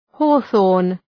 Προφορά
{‘hɔ:,ɵɔ:rn}